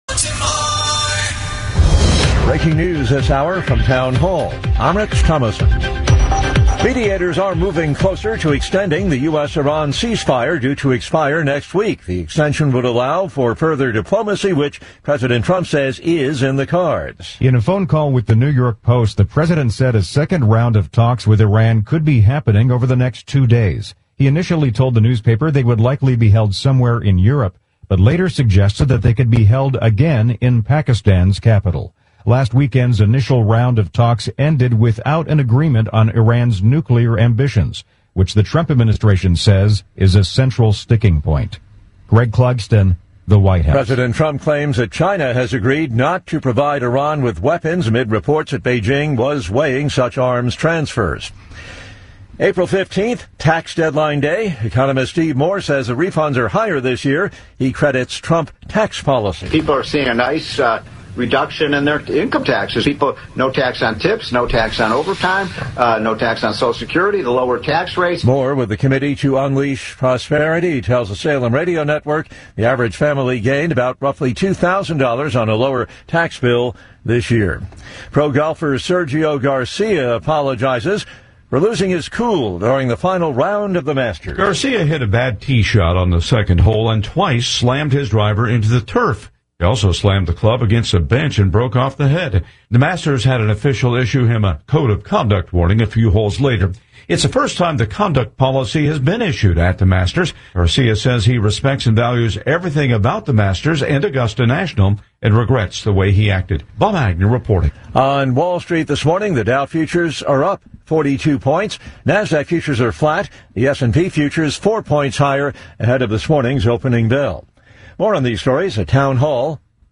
Kim Klacik LIVE 4-15-26